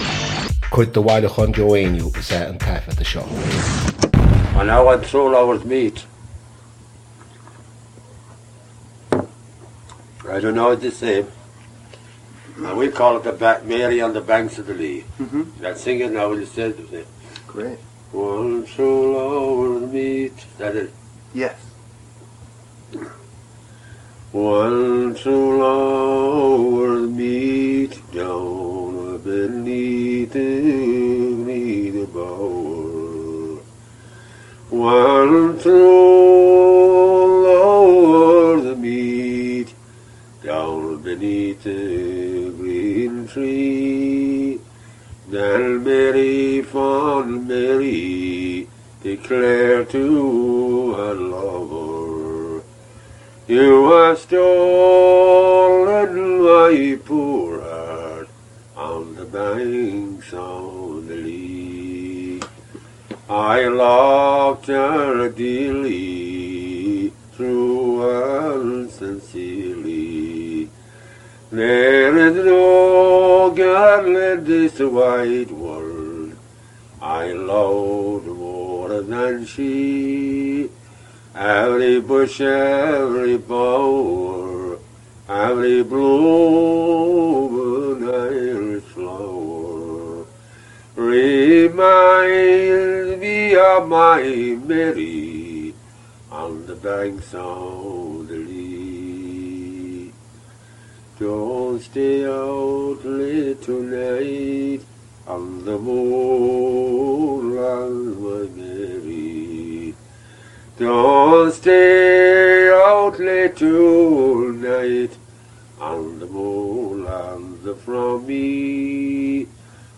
• Catagóir (Category): song.
• Ainm an té a thug (Name of Informant): Joe Heaney.
• Suíomh an taifeadta (Recording Location): Wesleyan University, Middletown, Connecticut, United States of America.